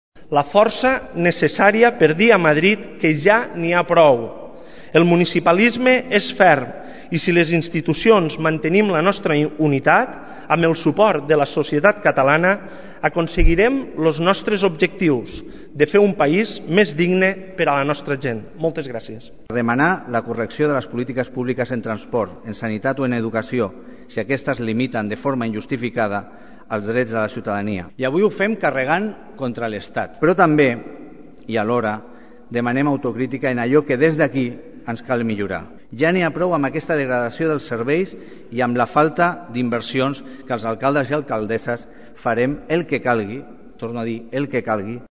L’acte ha aplegat més de 300 representants del món institucional, empresarial i sindical al Palau de la Generalitat, entre els assistents hi havia l’alcalde de Tordera, Joan Carles Garcia.